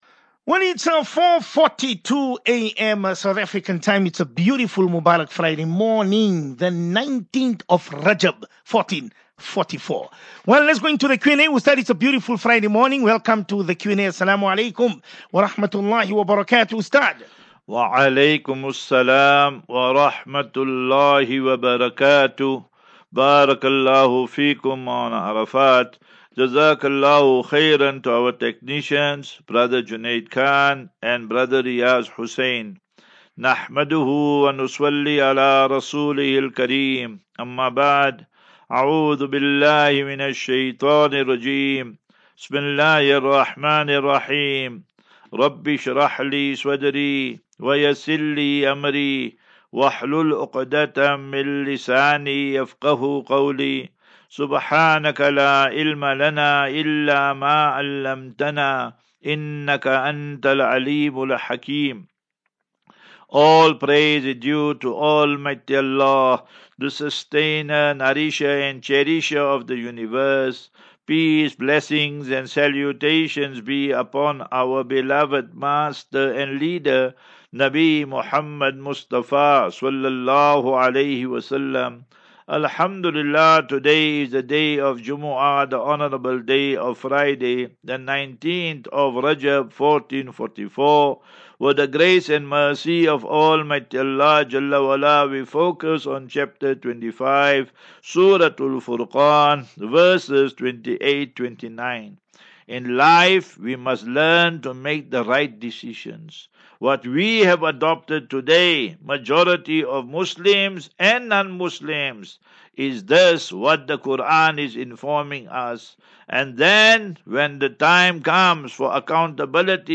View Promo Continue Install As Safinatu Ilal Jannah Naseeha and Q and A 10 Feb 10 Feb 23- Assafinatu-Illal Jannah 37 MIN Download